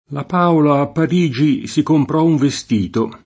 k1mpero]; che fu la forma tipica dell’uso fior. fino al ’500 — possibile molte volte uno scambio tra le due var. senza partic. motivi: la Paola a Parigi si comprò un vestito [